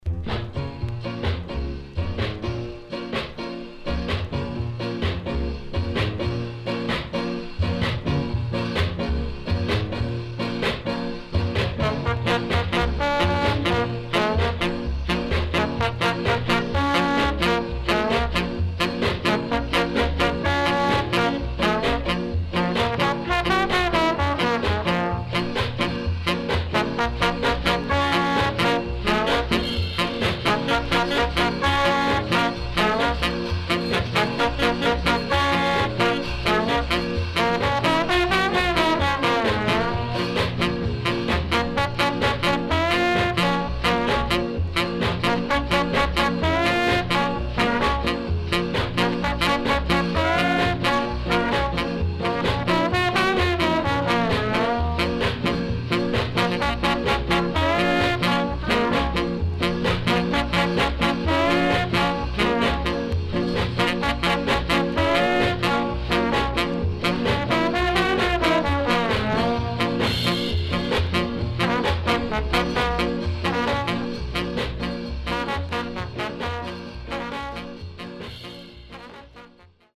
SIDE B:少しチリノイズ入りますが良好です。